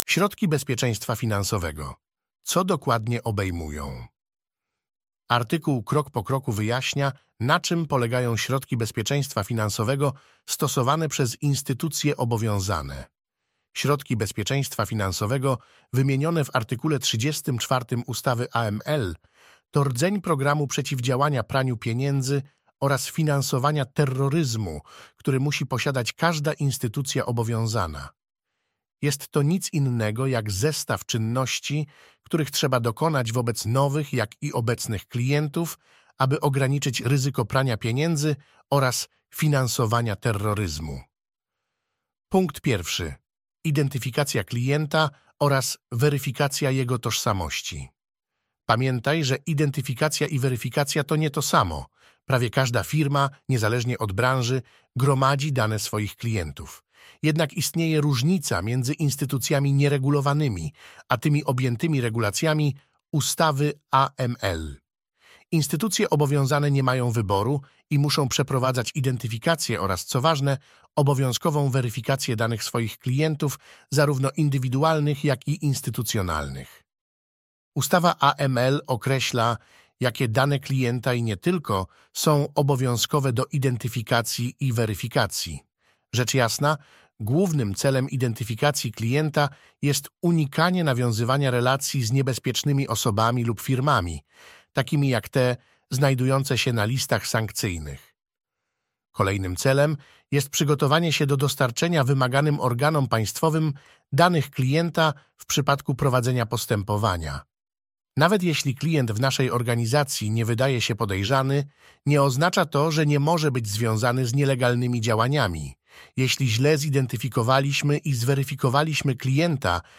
(głos lektora został wygenerowany przez sztuczną inteligencję)